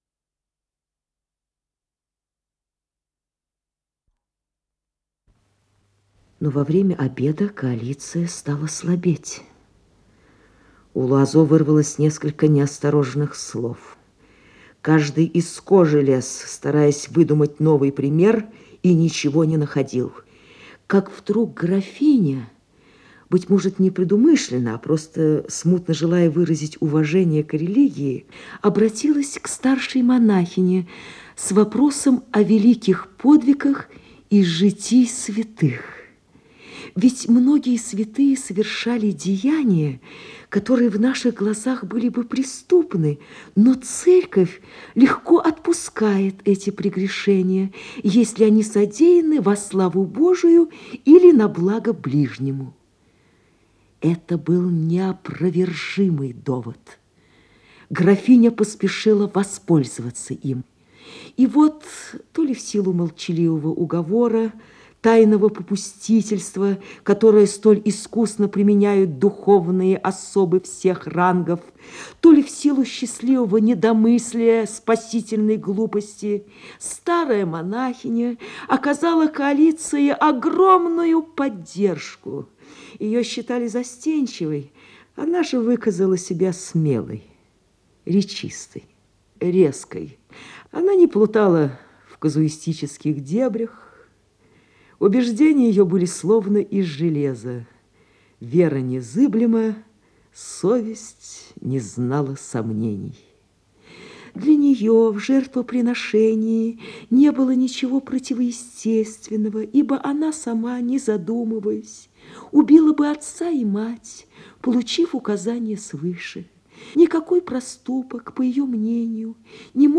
Исполнитель: Татьяна Доронина - чтение
Рассказ